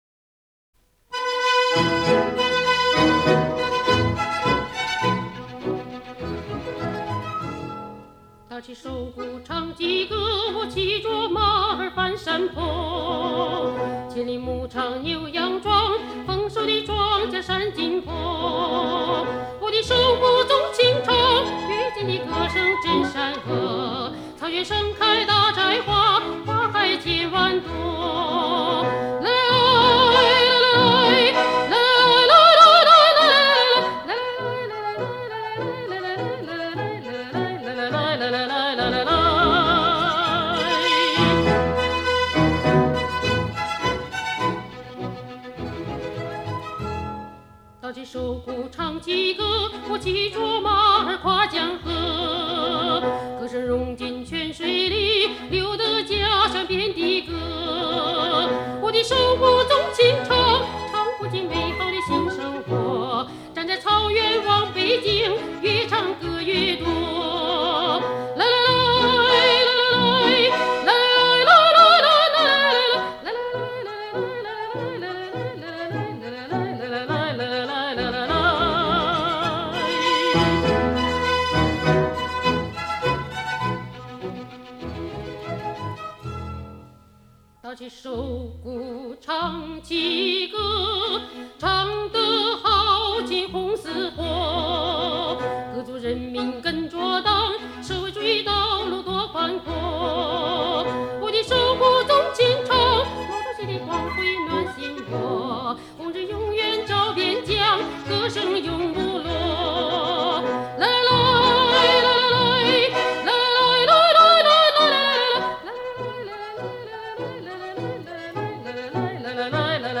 有改革开放的七十年代末以来，各个年代具有代表性的流行歌曲；